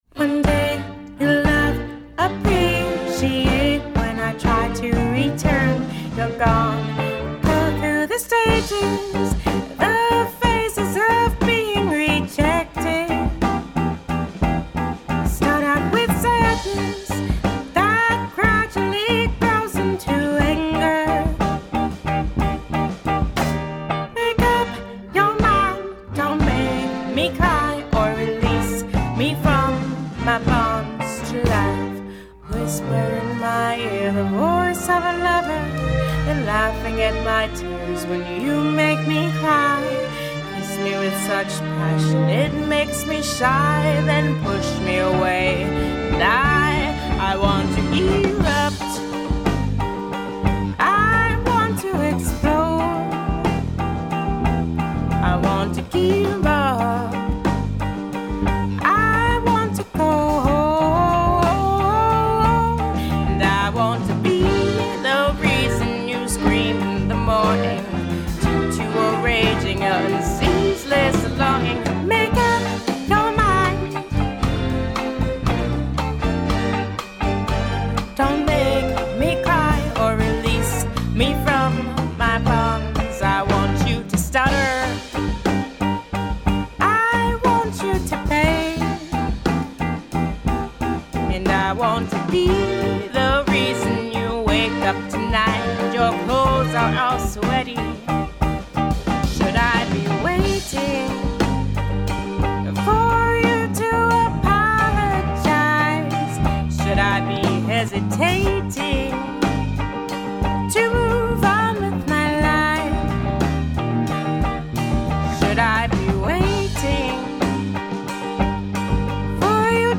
symphonic soul-pop